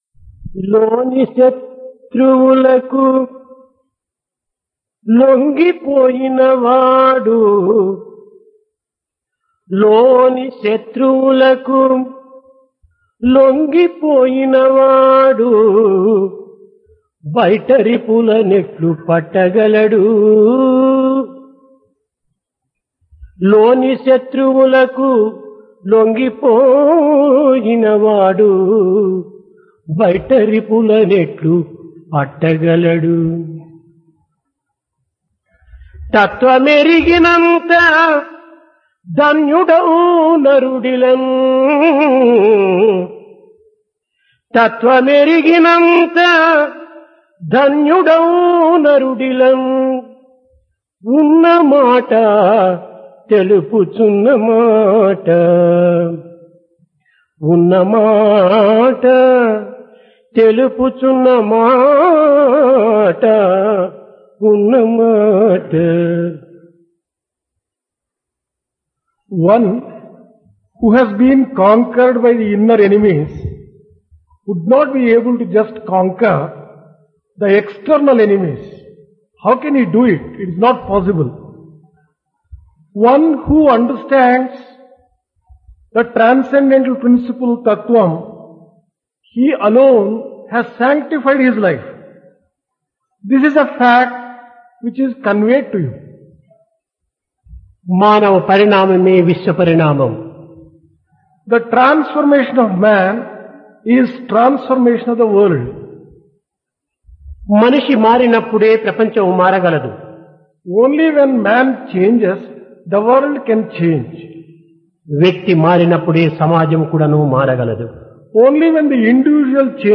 Dasara - Divine Discourse | Sri Sathya Sai Speaks
Divine Discourse of Bhagawan Sri Sathya Sai Baba
Place Prasanthi Nilayam